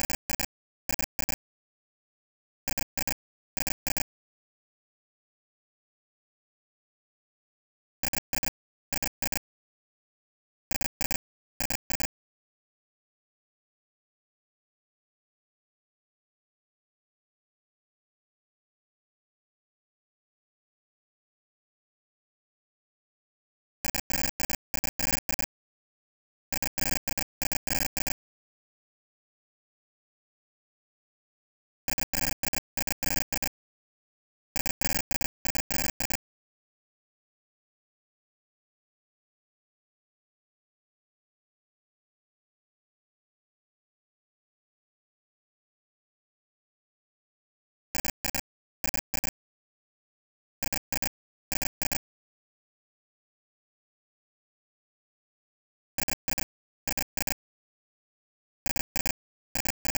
Algorithmic Music
(Sonification Piece)